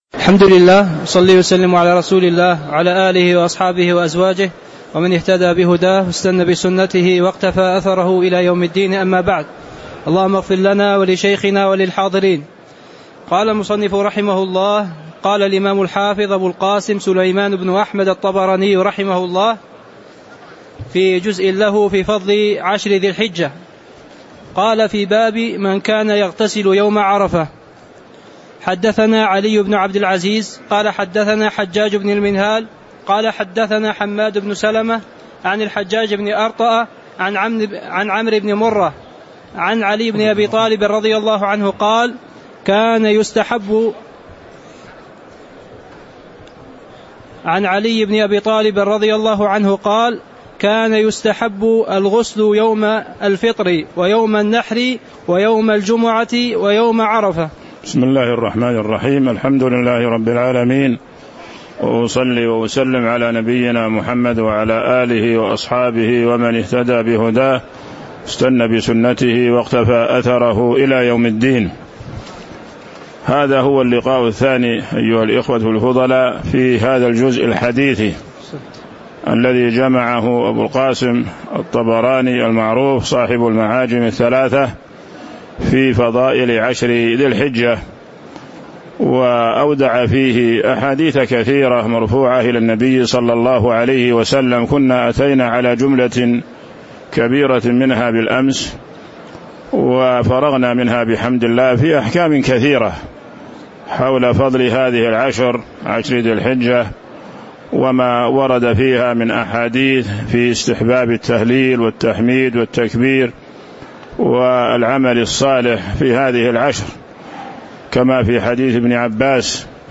تاريخ النشر ٢٩ ذو القعدة ١٤٤٦ هـ المكان: المسجد النبوي الشيخ